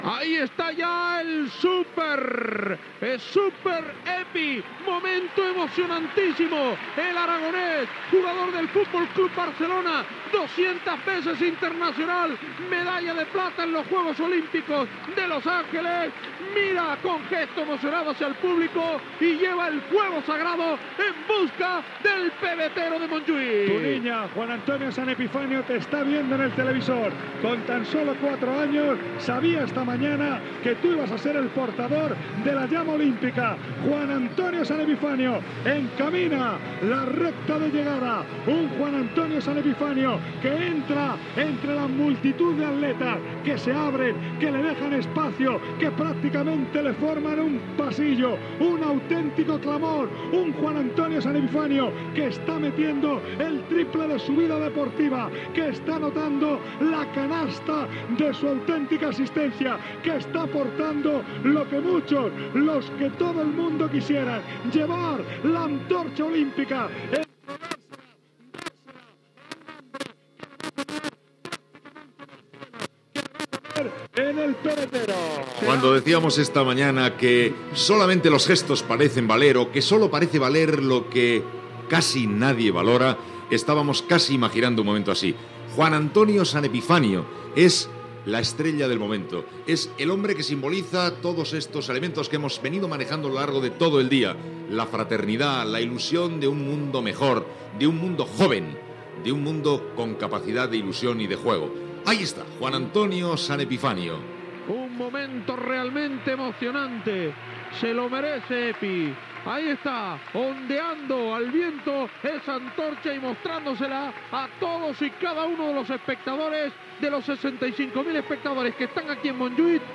Narració de la cerimònia inaugural dels Jocs Olímpics de Barcelona, des de l'Estadi Olímpic.
Esportiu
Gabilondo, Iñaki
Lama, Manolo